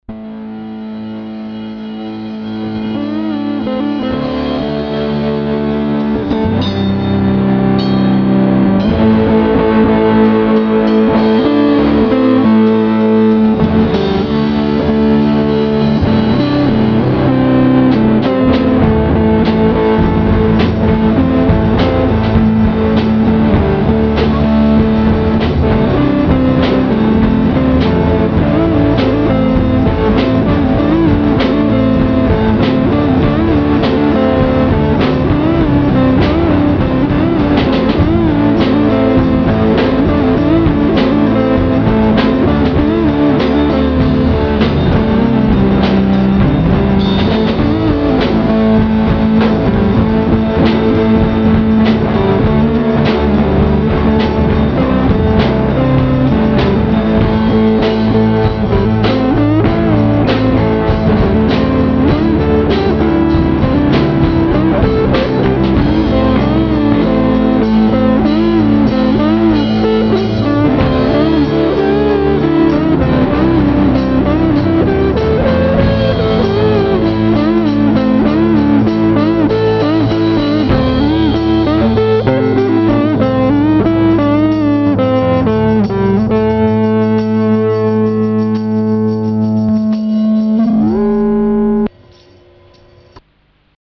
gesang, djembe
drums
gitarren, bass